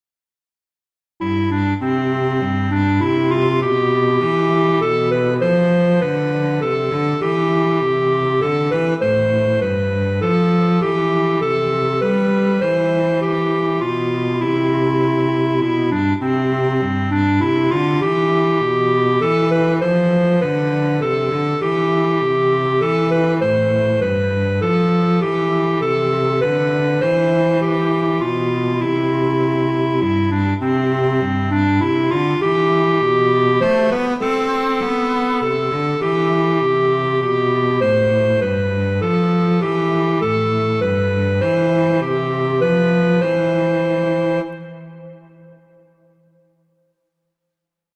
arrangements for clarinet and cello